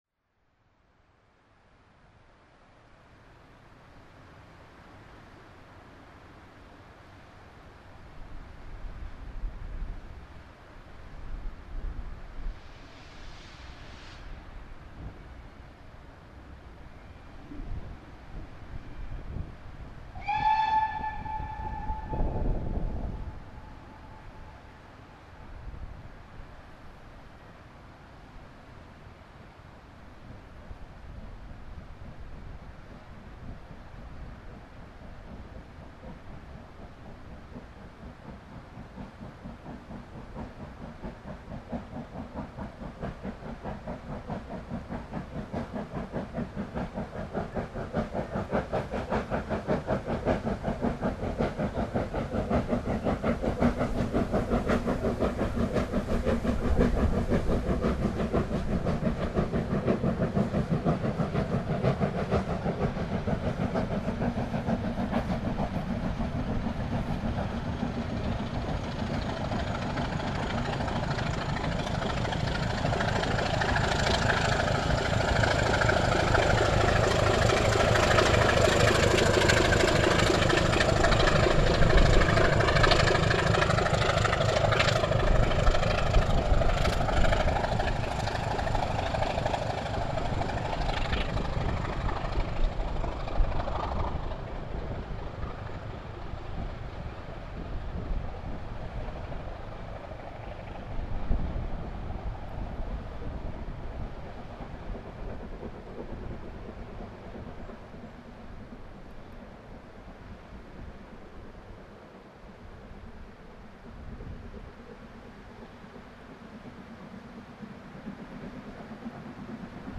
Given the lack of much noise on the Esk Valley, we decided  to record the departure out of Grosmont with the Schools again at the head.  At least in this one you can  hear the 4-4-0 working well on the start out of the station and even after the diesel gives a bit of a push,  the 3 cylinder beat drifts back to the microphone as the train heads up the Esk Valley again.